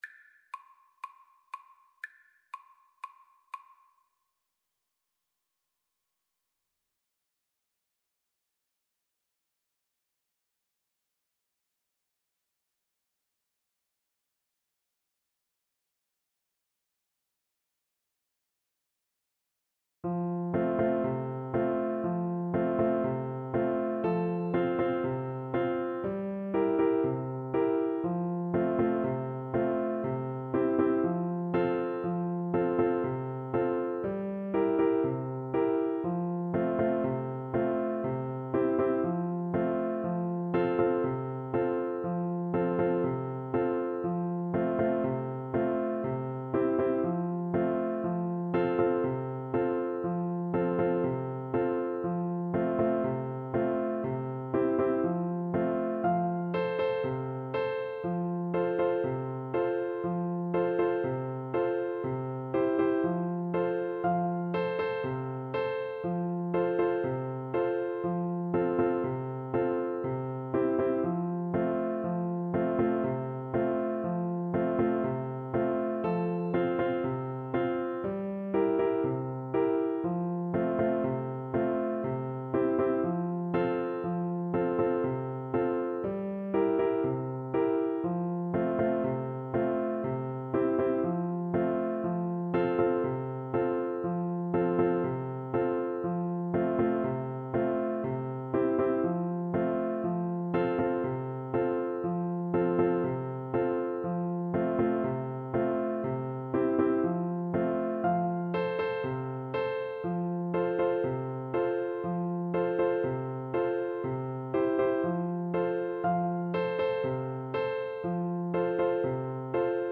Clarinet version
Fast =c.120
4/4 (View more 4/4 Music)
C5-F6
Caribbean Music for Clarinet